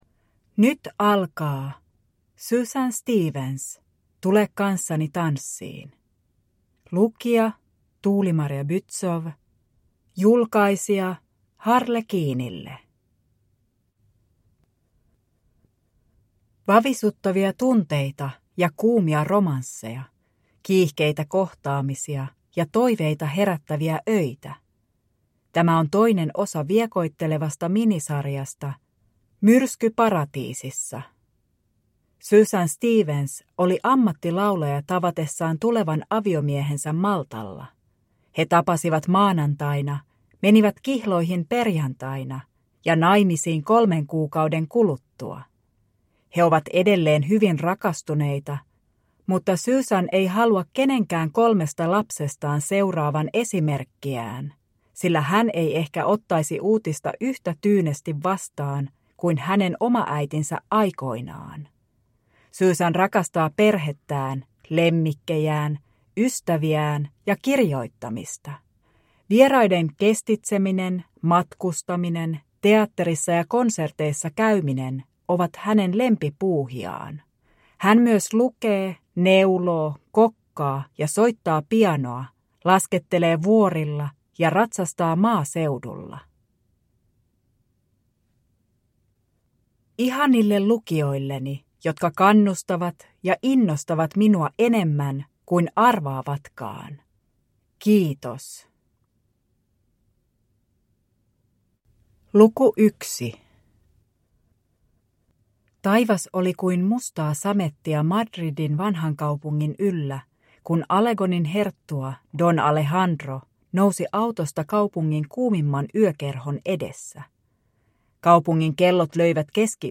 Tule kanssani tanssiin – Ljudbok – Laddas ner